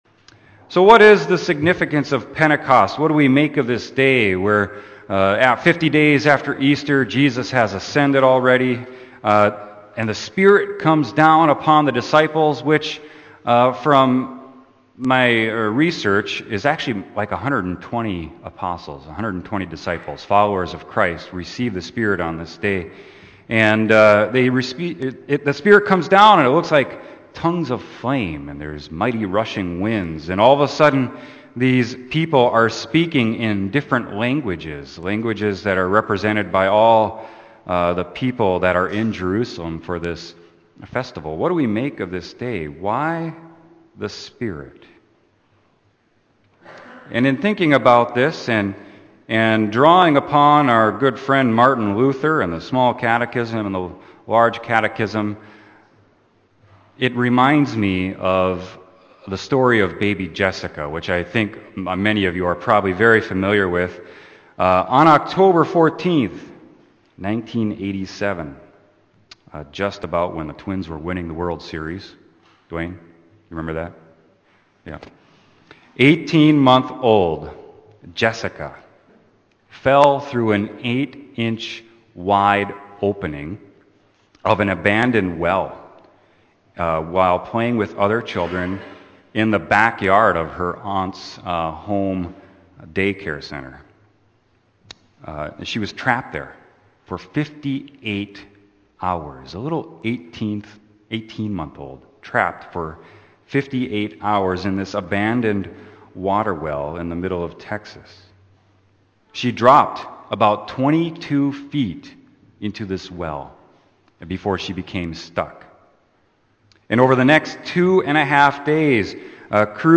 Sermon: Acts 2.1-21